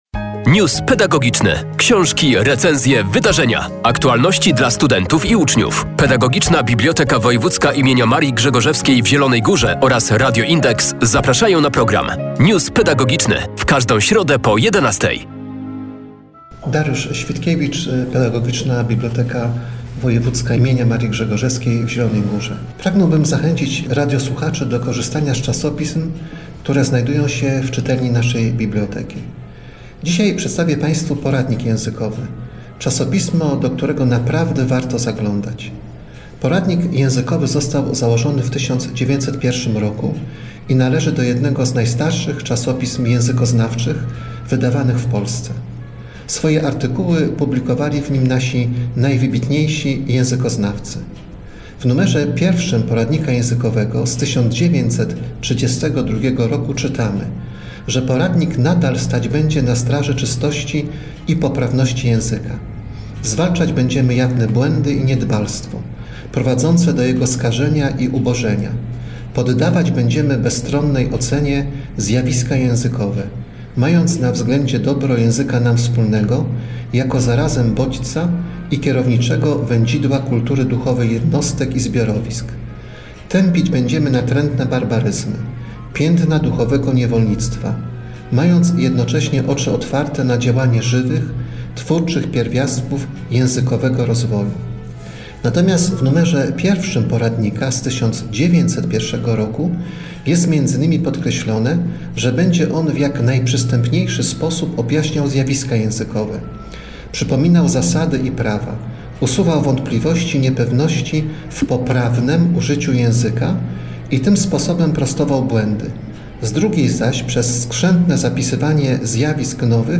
Pedagogiczna Biblioteka Wojewódzka im. Marii Grzegorzewskiej w Zielonej Górze poleca czasopismo językowe – nie tylko dla nauczycieli, ale także studentów i wszystkich, którzy chcą rozwijać wiedzę o polszczyźnie. News pedagogiczny.